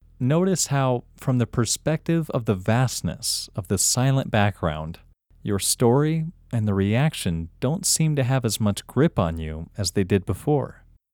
OUT – English Male 20